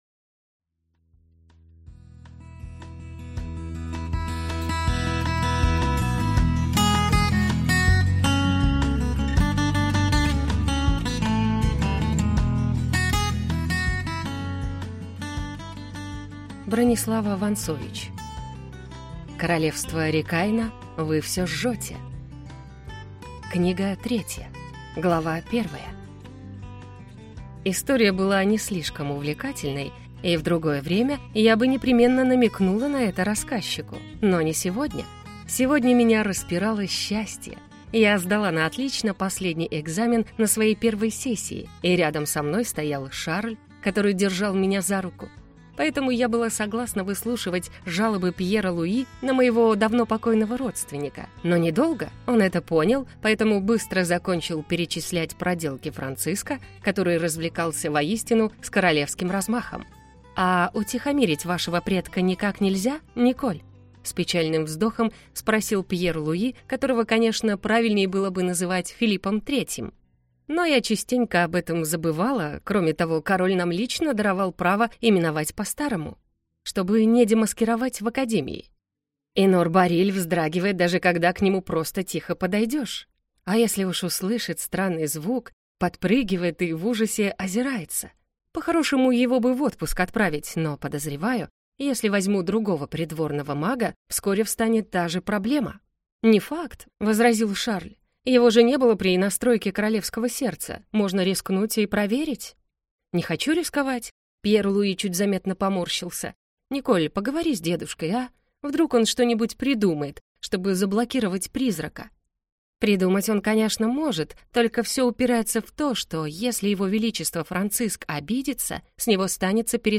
Аудиокнига Вы всё жжёте! Книга 3 | Библиотека аудиокниг